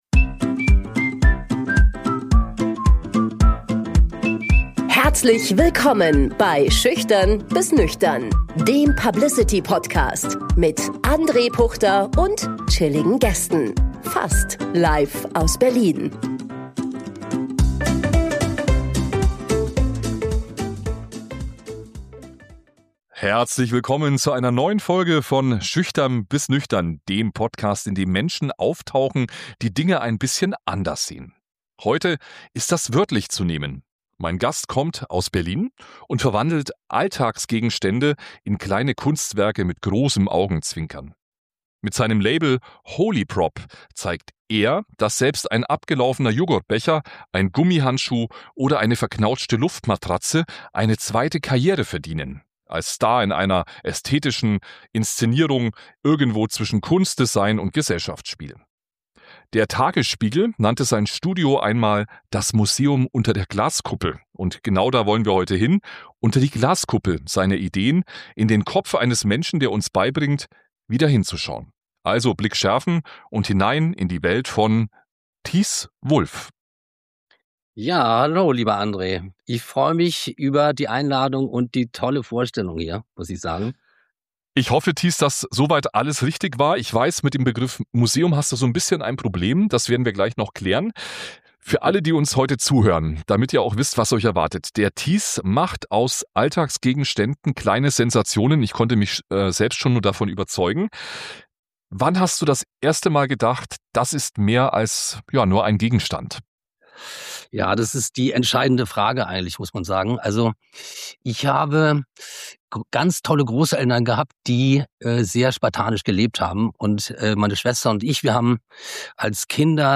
Ein Austausch über Wahrnehmung, Wertigkeit und die Kunst, im Alltäglichen das Besondere zu entdecken.